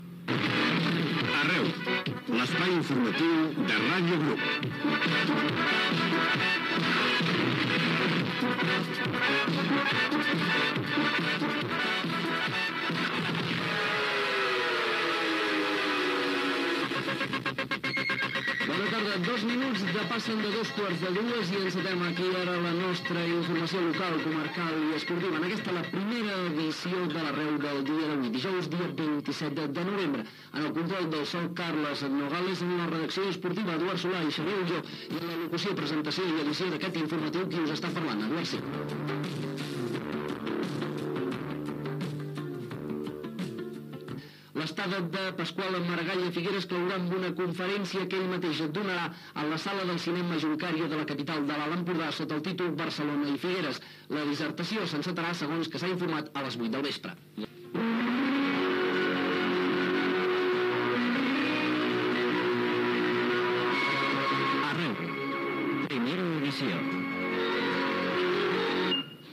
Careta del programa, hora, equip, l'alcalde de Barcelona Pasqual Maragall farà una conferència a Figueres, indicatiu del programa.
Informatiu